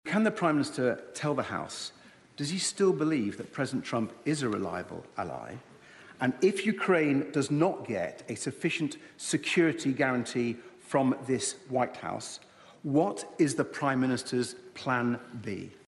Sir Ed Davey in PMQs